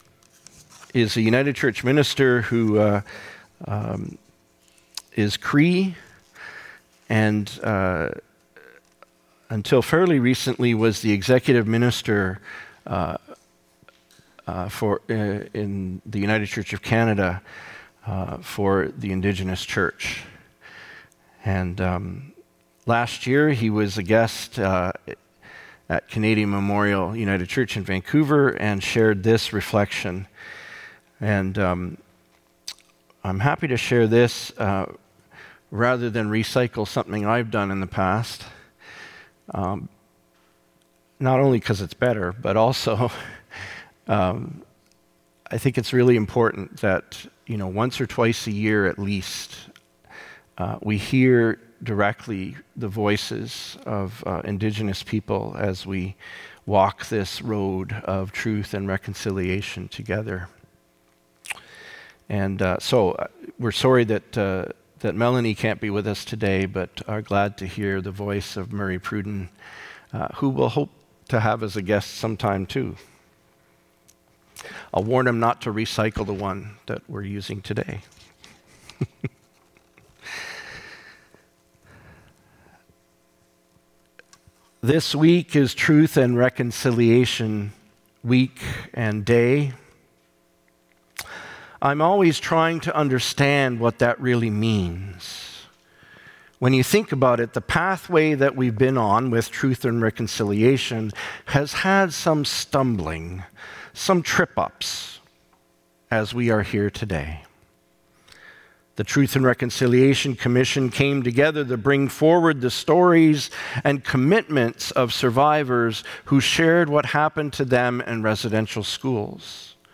Sermons | Gilmore Park United Church
September 28 2025 - Reflection